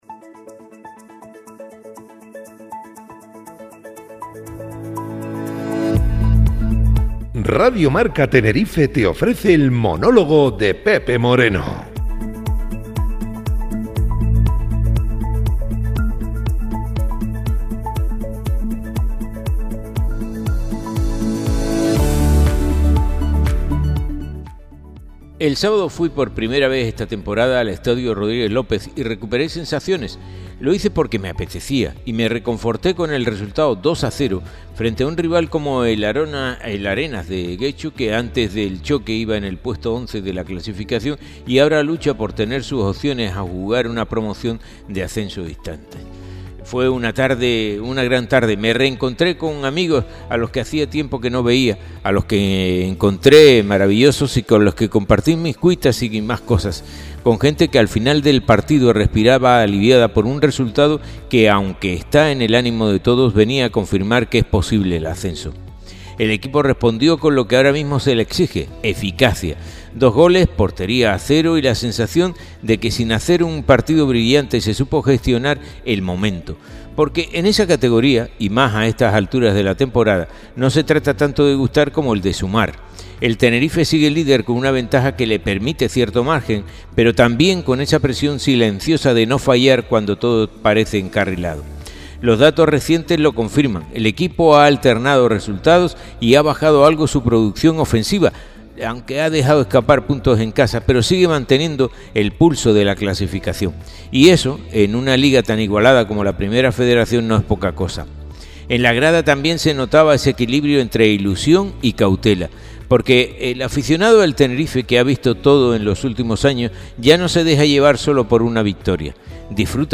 El monólogo